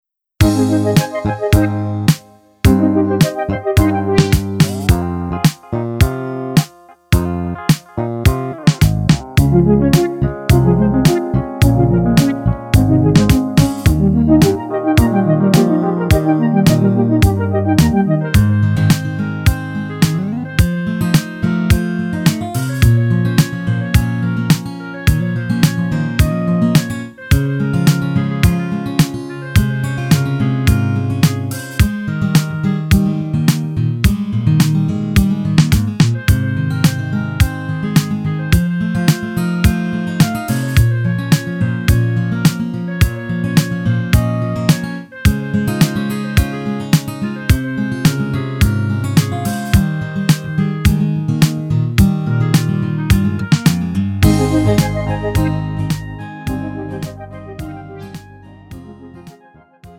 음정 -1키
장르 pop 구분 Pro MR